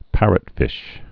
(părət-fĭsh)